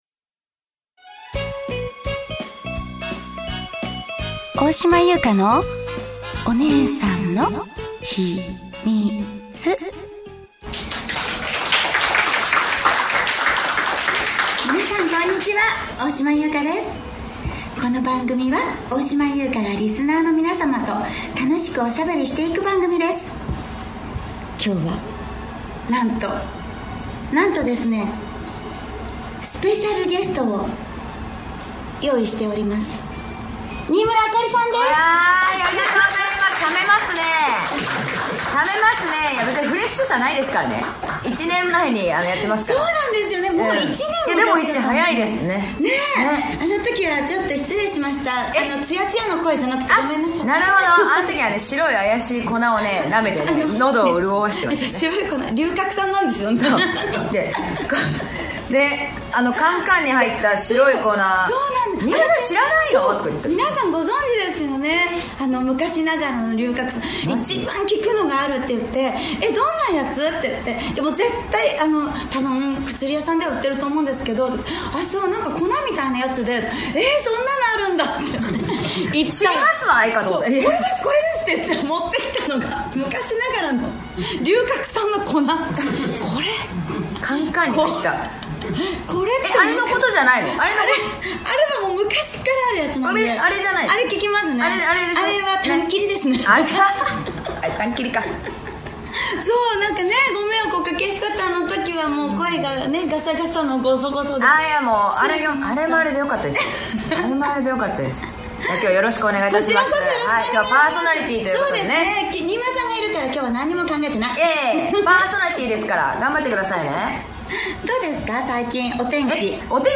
とコラボ公開収録が開催されました♪お二人でのトークは2回目となりますが今回も相性抜群ばっちり・・(笑)！？